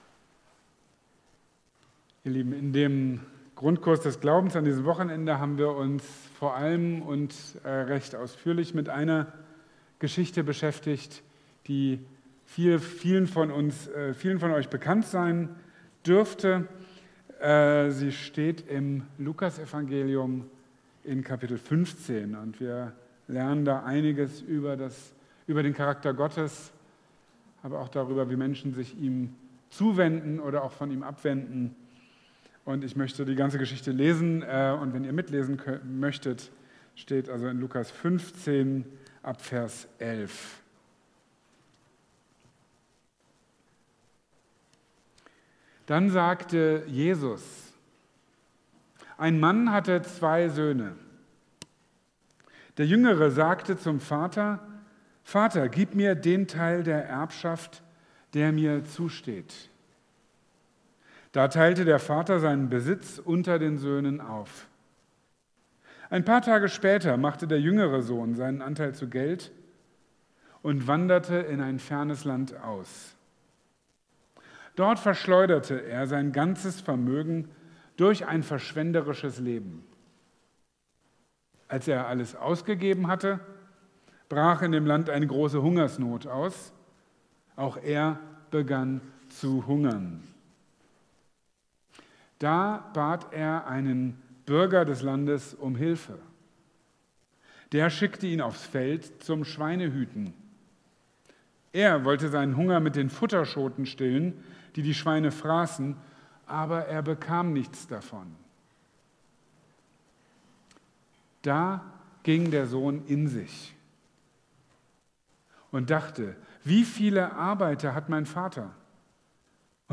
Die beste Entscheidung meines Lebens | Marburger Predigten